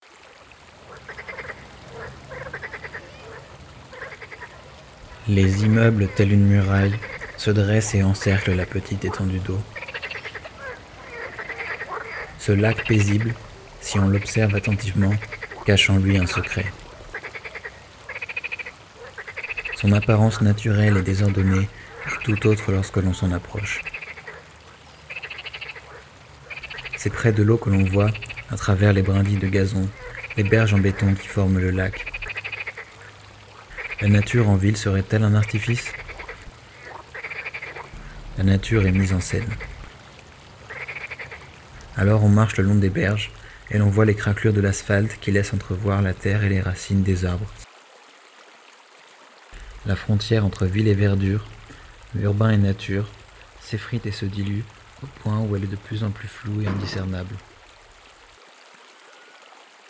Audio d'une minute auprès d'un lac à Champs-sur-marne.
Une minute près du lac